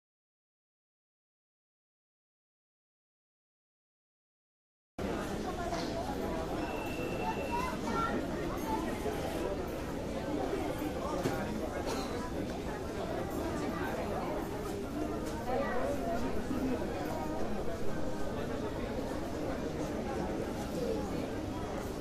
Звуки аэропорта, атмосфера
Шум толпы в аэропортном зале ожидания